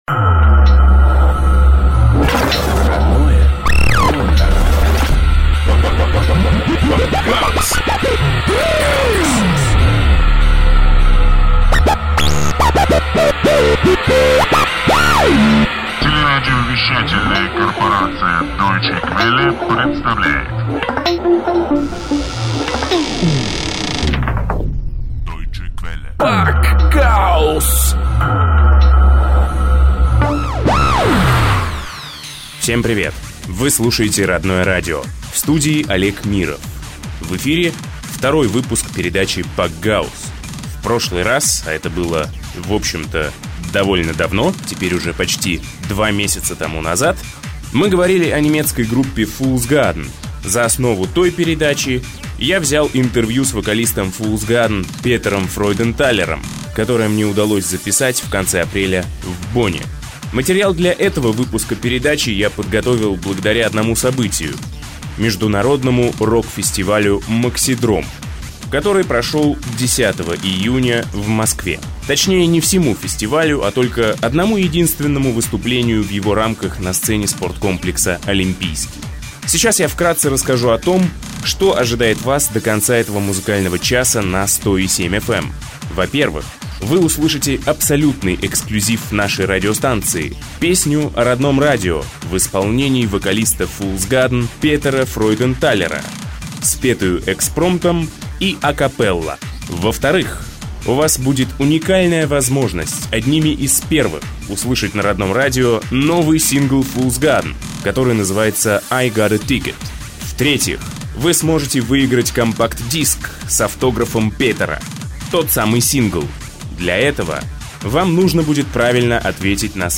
Fool's Garden Ещё одно эксклюзивное интервью. FG - участники фестиваля Максидром-2006.